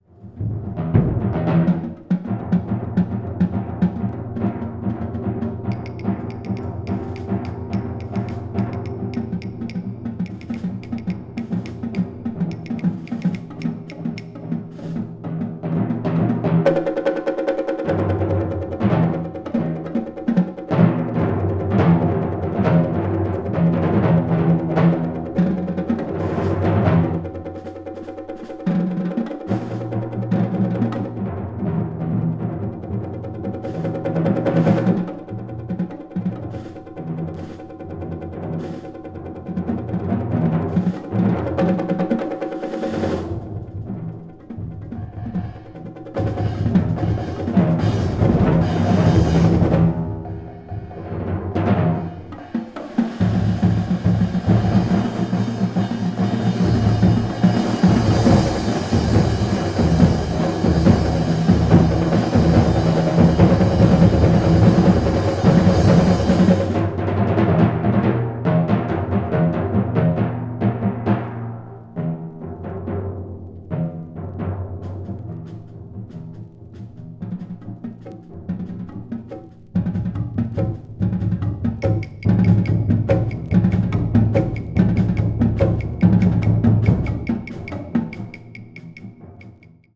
media : EX/EX(わずかにチリノイズが入る箇所あり)
音質も素晴らしいです。